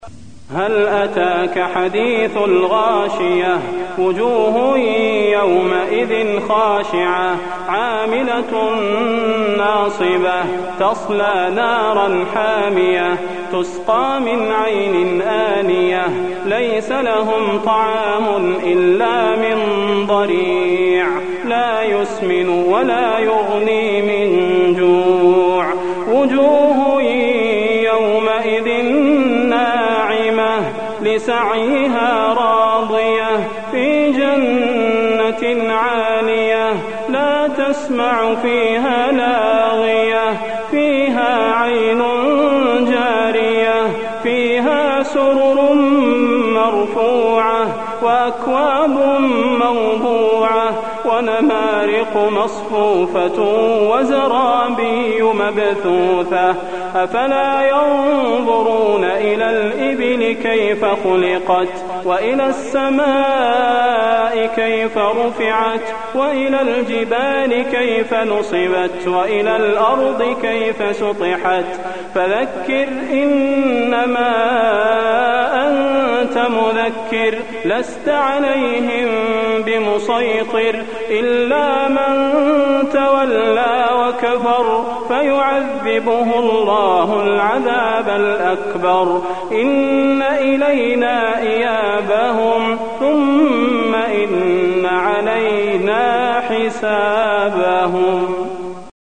المكان: المسجد النبوي الغاشية